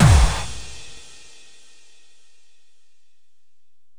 Big Drum Hit 03.wav